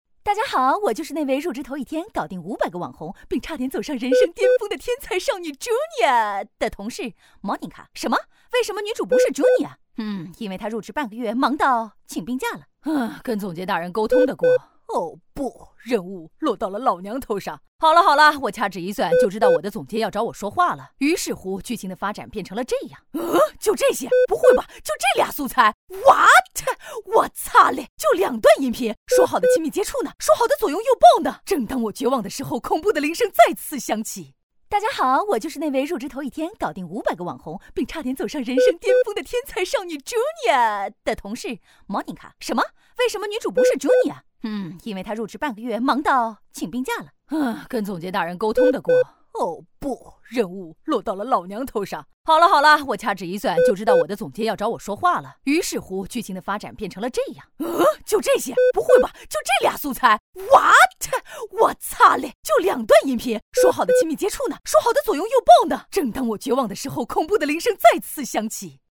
• 女S155 国语 女声 飞碟说MG-H5风格 快速 幽默搞笑 积极向上|时尚活力|亲切甜美|脱口秀